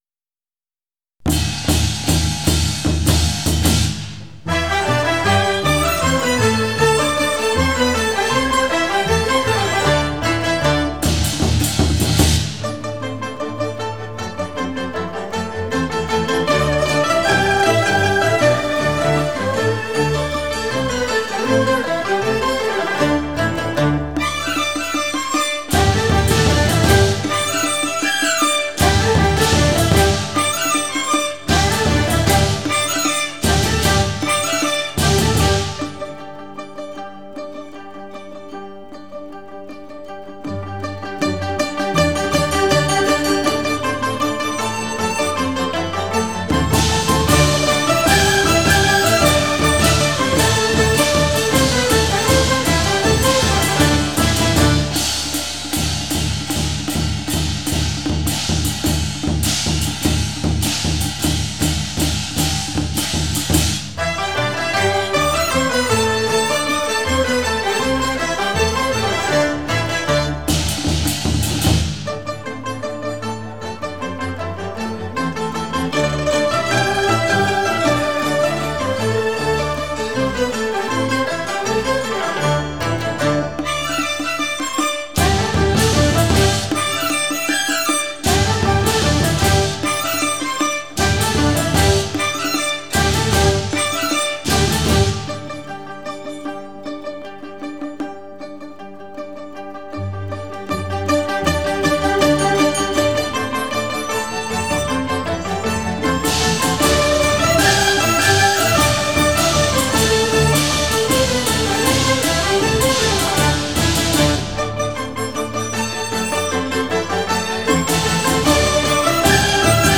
音乐类型：民乐